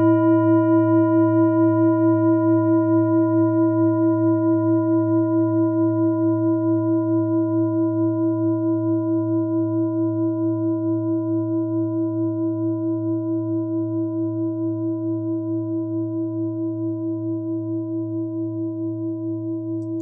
Chakrahealing Klangschalen - Tibet Klangschale Nr.26, Planetentonschale: Wasserstoffgamma, 6.Chakra (Drittes Auge) und 7.Chakra (Scheitelchakra)
Klangschalen-Durchmesser: 24,0cm
(Ermittelt mit dem Filzklöppel oder Gummikernschlegel)
Die Klangschale hat bei 156.73 Hz einen Teilton mit einer
Die Klangschale hat bei 159.42 Hz einen Teilton mit einer
Die Klangschale hat bei 466.06 Hz einen Teilton mit einer
Die Klangschale hat bei 469.23 Hz einen Teilton mit einer
klangschale-tibet-26.wav